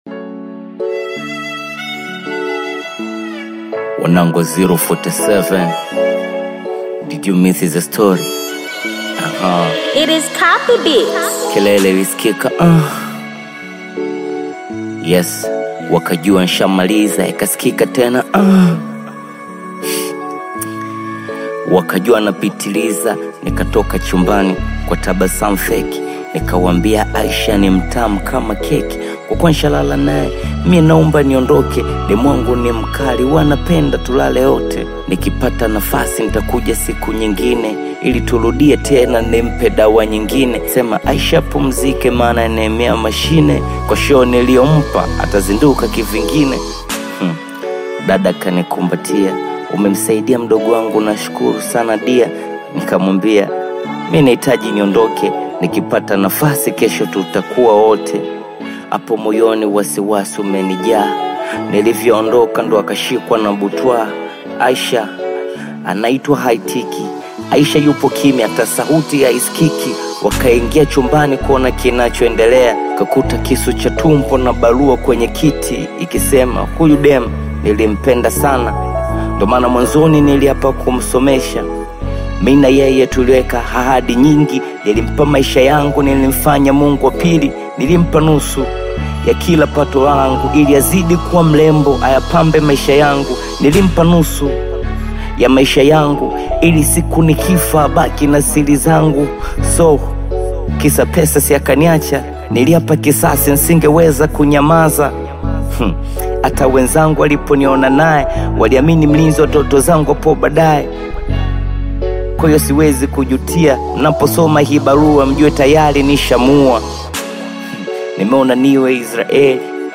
Bongo Flava music track
Tanzanian Bongo Flava artist, singer, and songwriter
Bongo Flava song